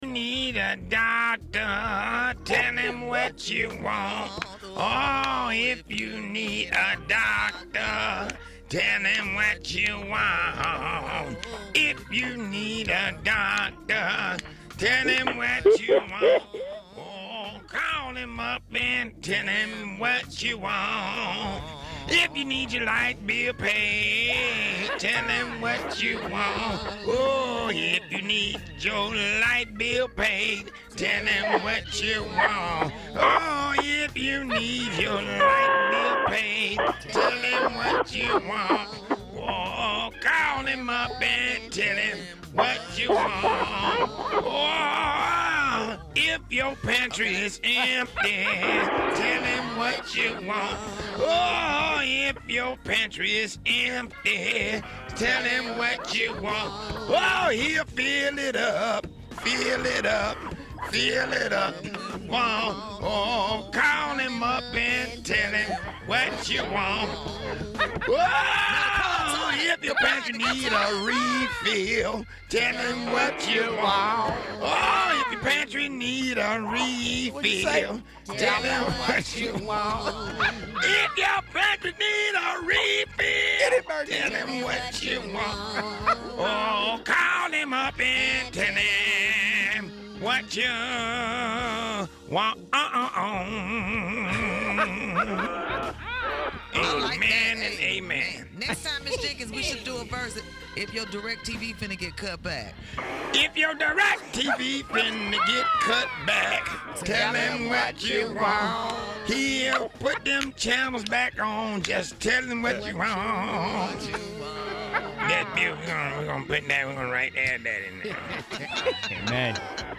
CLICK TO HEAR THE CHURCH ANNOUNCEMENTS!!